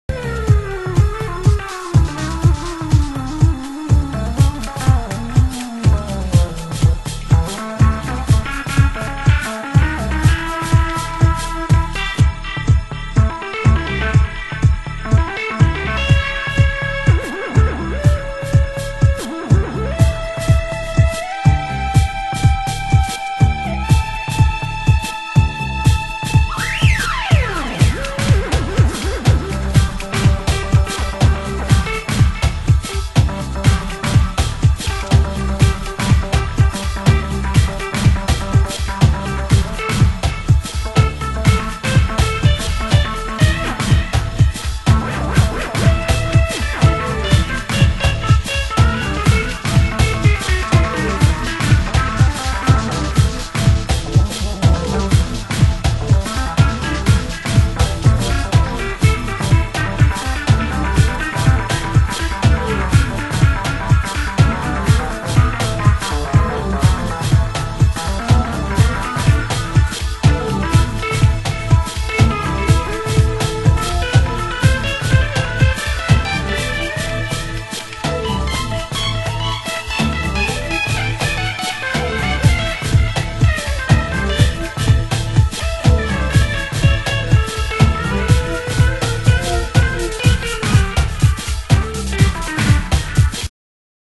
Techno Mix